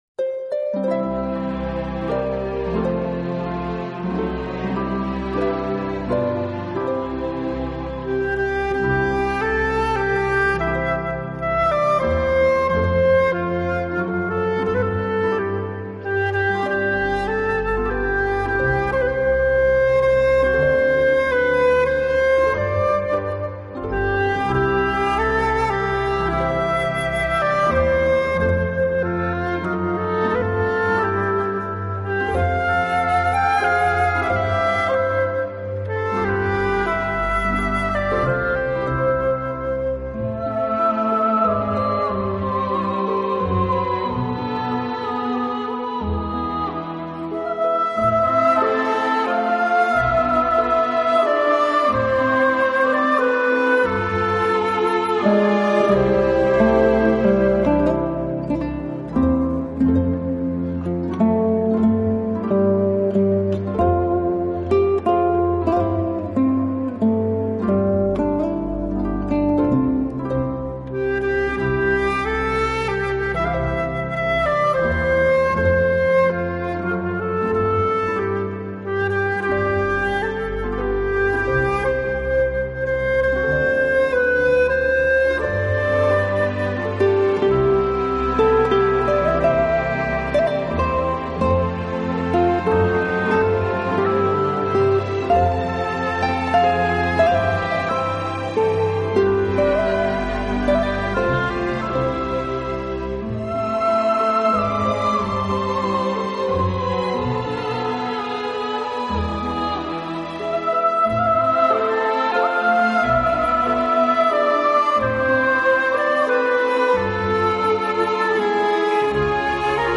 【轻音合辑】
Flute, with Orchestra